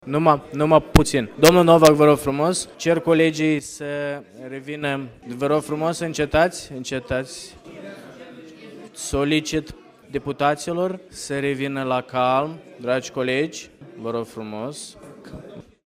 Așa cum spuneam, mai mulți aleși s-au ridicat de pe scaune și au început să se îmbrâncească în timpul dezbaterilor.